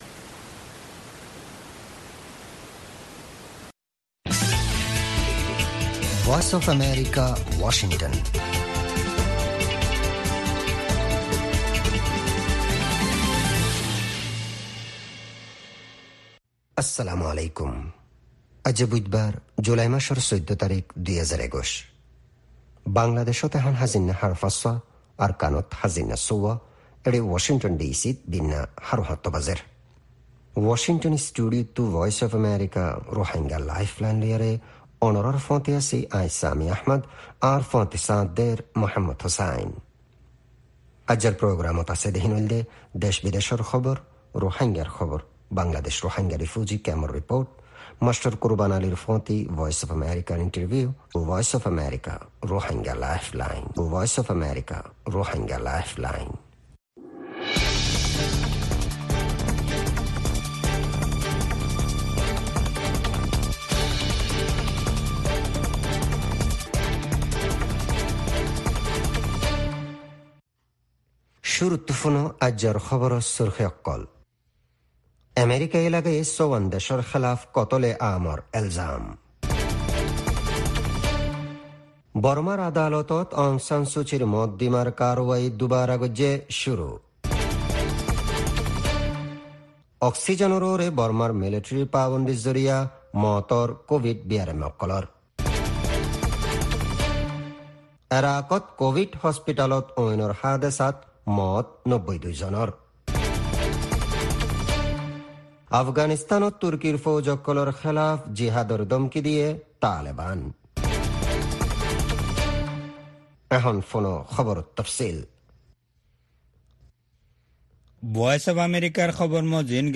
News Headlines